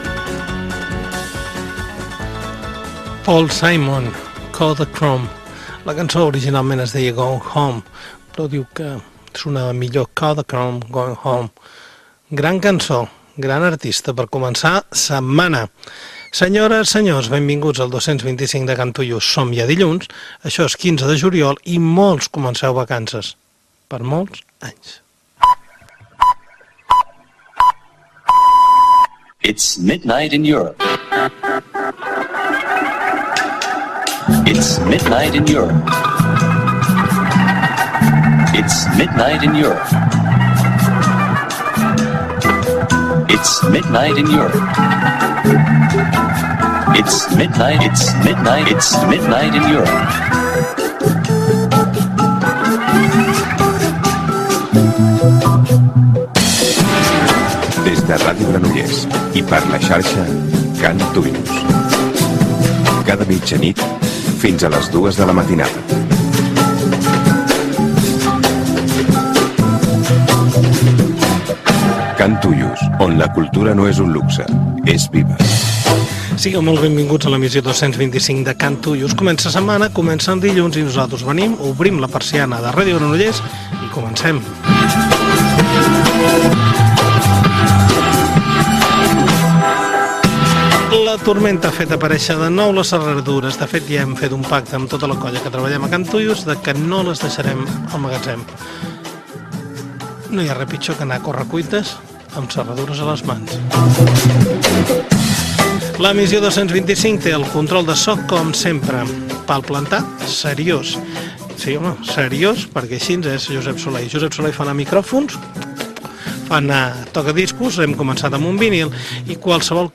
Inici del programa, data, careta, les emissores de La Xarxa, contacte amb el programa, el cap de setmana, tema musical
Musical